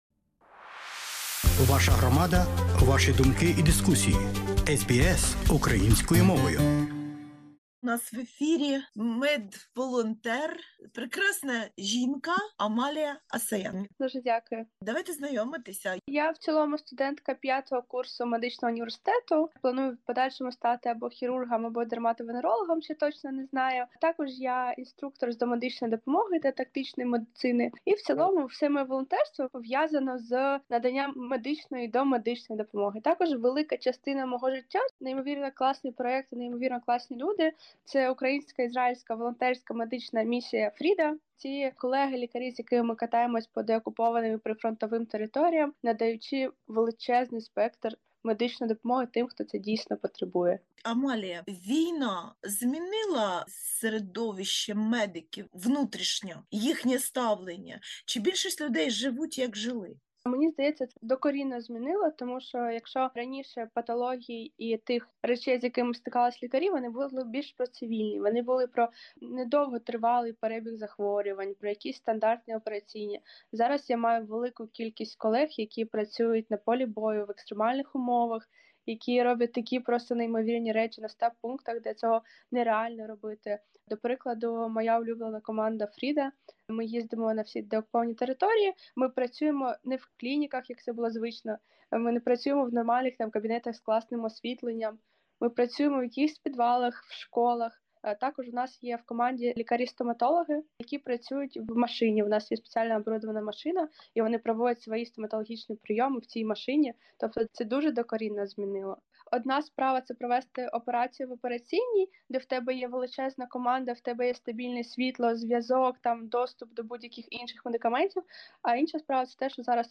SBS Українською